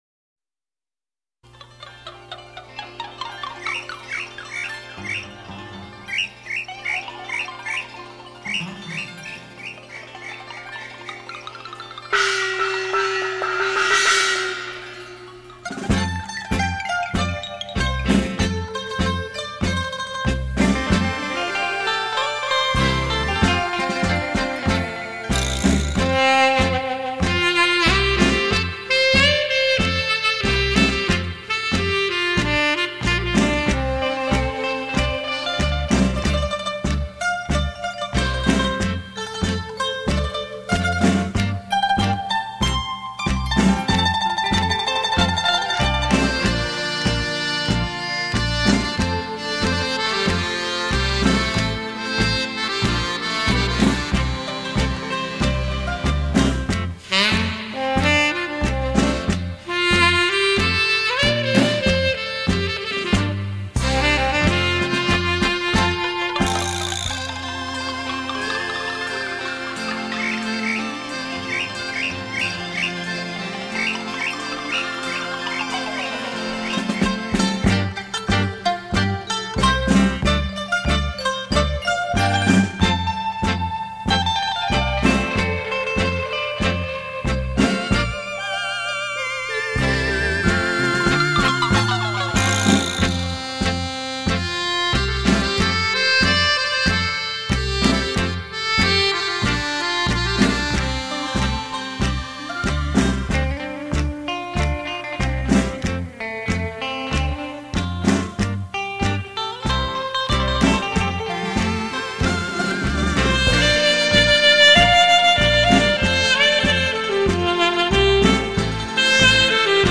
东洋音乐 五